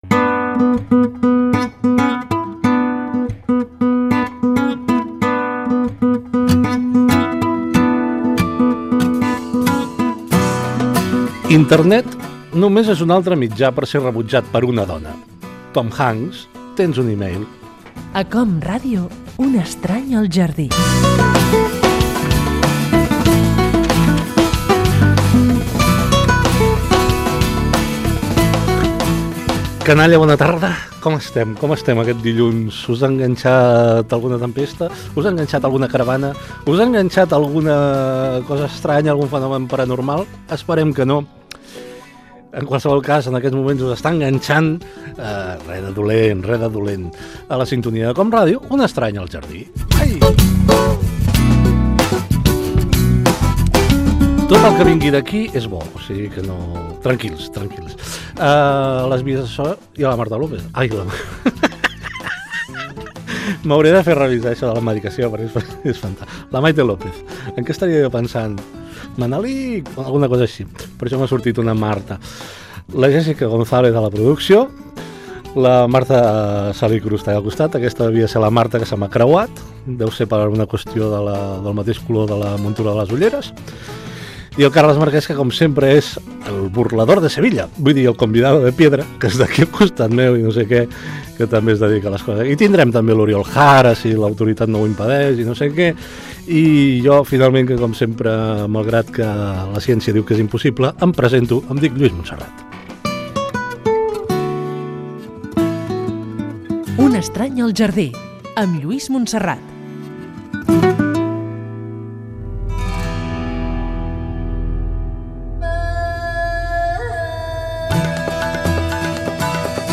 Frase, indicatiu del programa, inici, equip i sumari del magazín de cultura i oci.